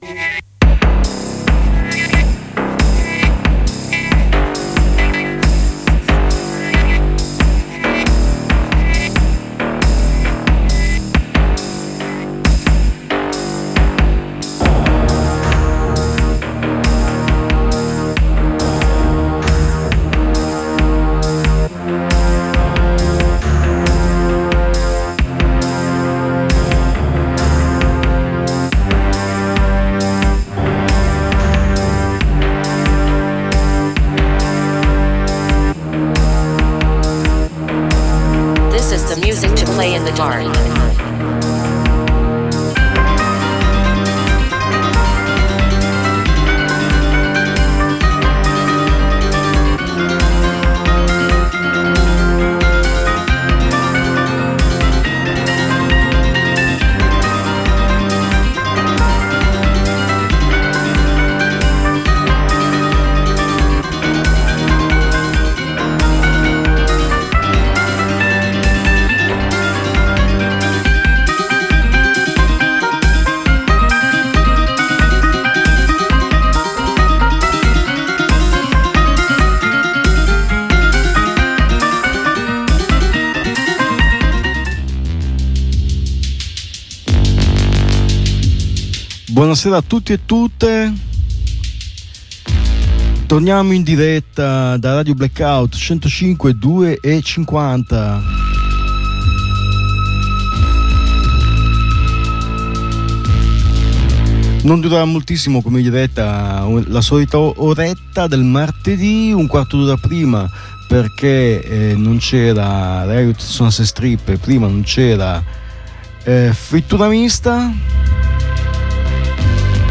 Per un’ora verrete condotti attraverso un percorso trasversale fatto da sonorità che non si fermano ad un genere: si può passare dall’industrial alla wave, facendo una fermata nel punk, nel death metal, nell’electro oppure anche nel math rock. Seguiremo le storie di chi ha fatto dei suoni non convenzionali l’espressione della propria persona con ascolti ed alle volte con interviste.